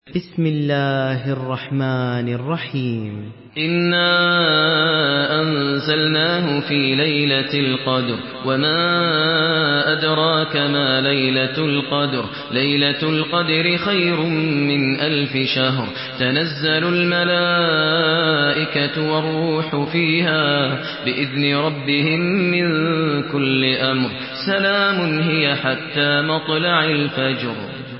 Surah আল-ক্বাদর MP3 in the Voice of Maher Al Muaiqly in Hafs Narration
Murattal Hafs An Asim